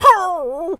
pgs/Assets/Audio/Animal_Impersonations/dog_hurt_whimper_howl_14.wav at master
dog_hurt_whimper_howl_14.wav